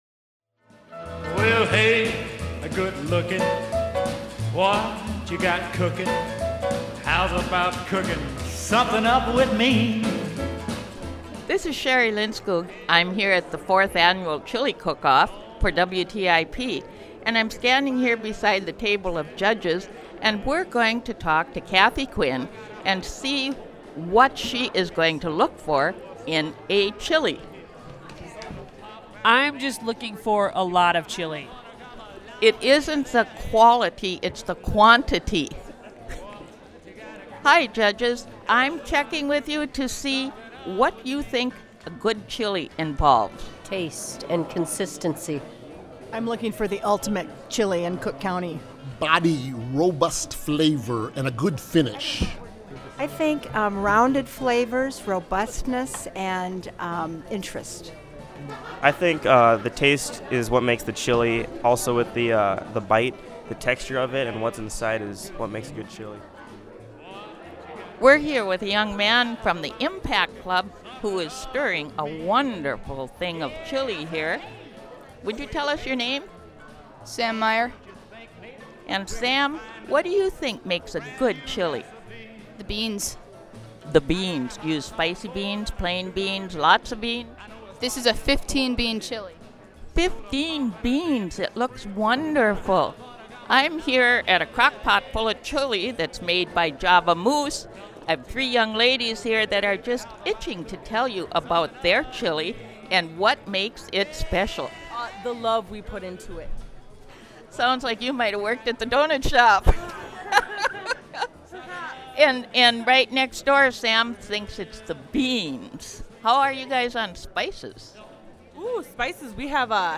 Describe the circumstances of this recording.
The 4th Annual Cook County Chili Cook Off was held Saturday, February 23, 2013. Over twenty different types of chili were entered into the competition.